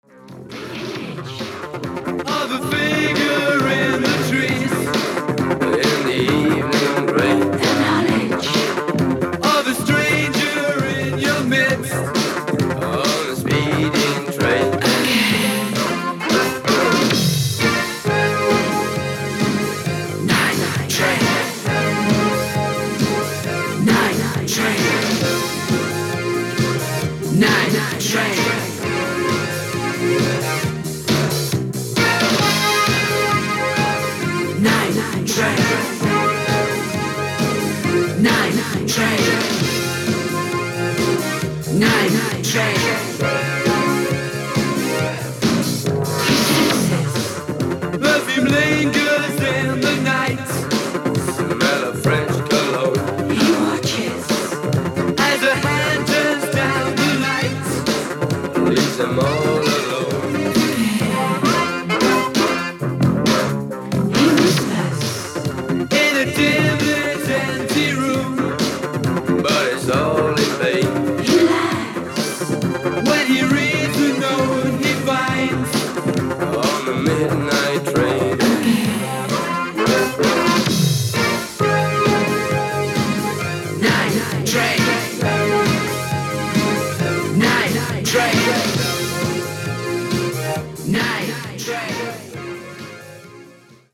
80's POP感もあってノリノリ！！！